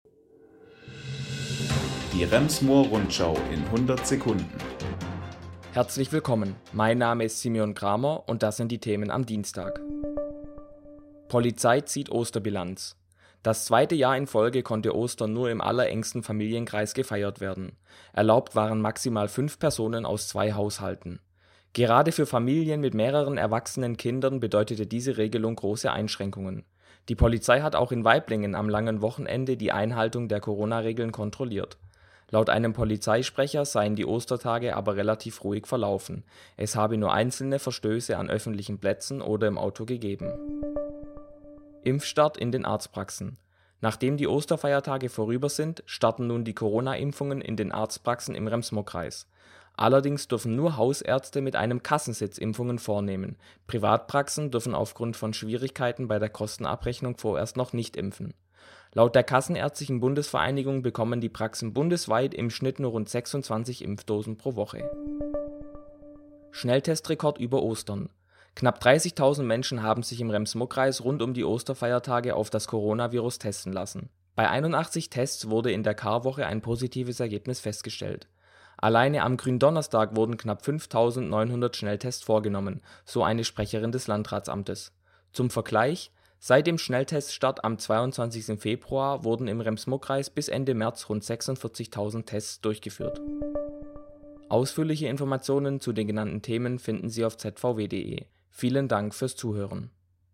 Die wichtigsten Nachrichten des Tages